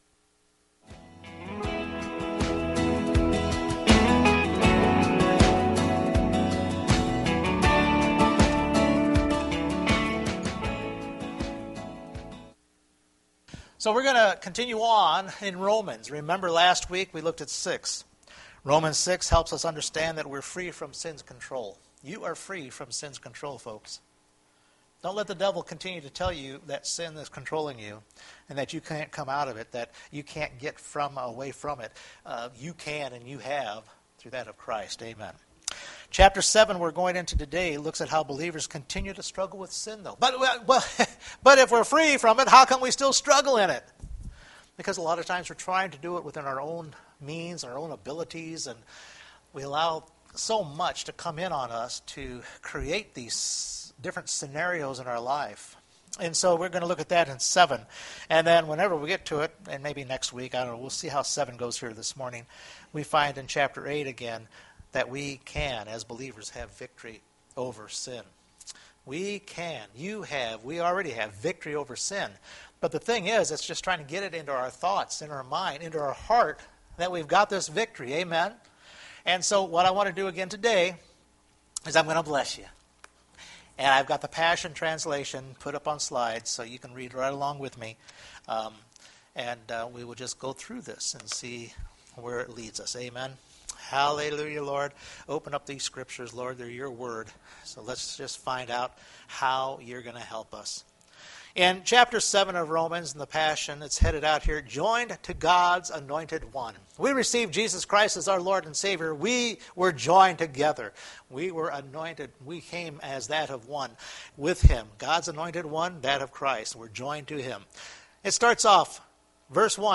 Romans Chapter 7 Service Type: Sunday Morning We look at Romans Chapter 7 to help us as we continue to have struggles in the area of sin.